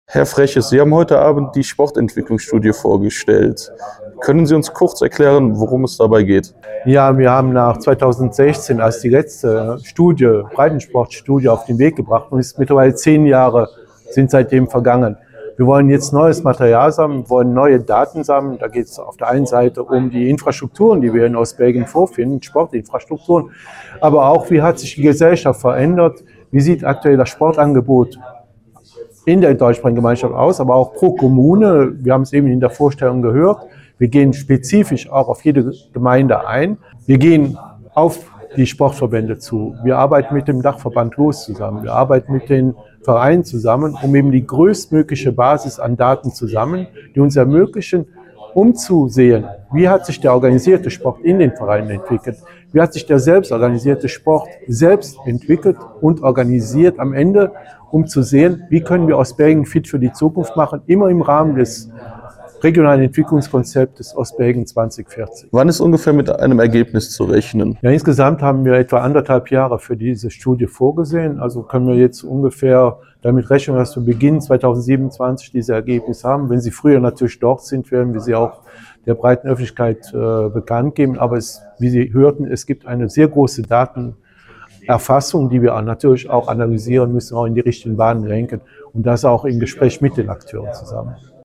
mit Minister Gregor Freches unterhalten.